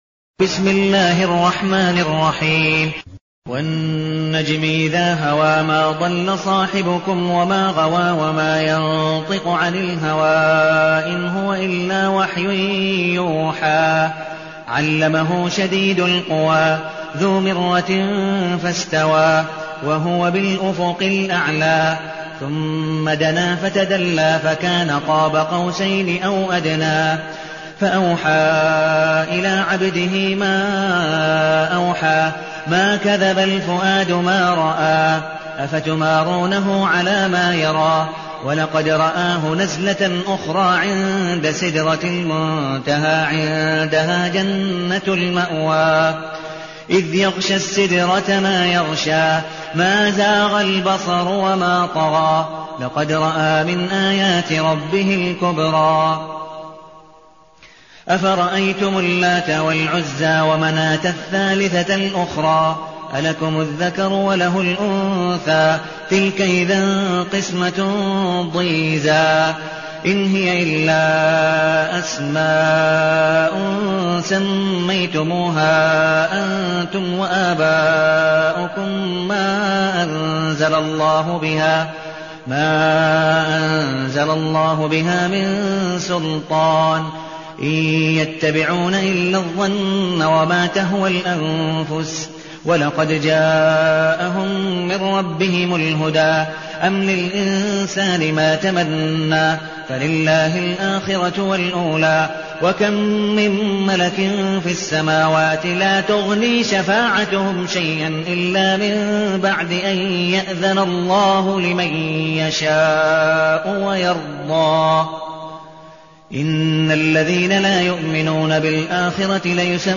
المكان: المسجد النبوي الشيخ: عبدالودود بن مقبول حنيف عبدالودود بن مقبول حنيف النجم